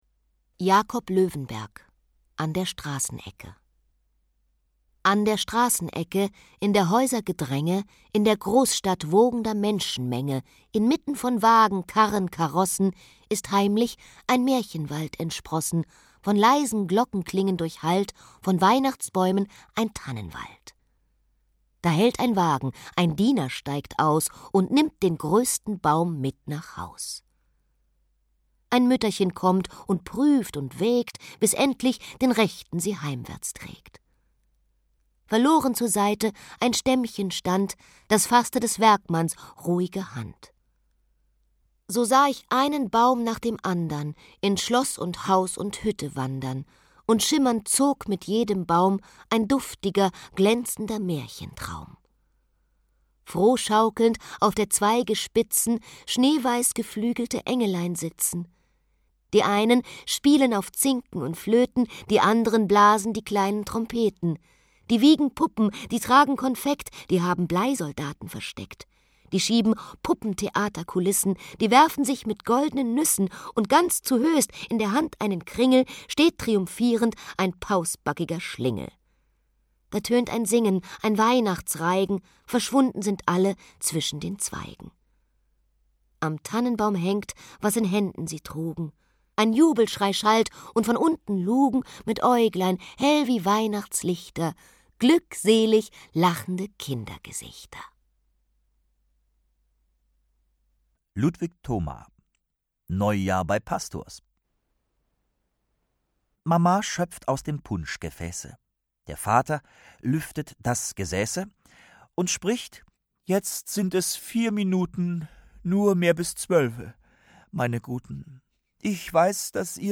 Winterzauber Der Audiobuch-Adventskalender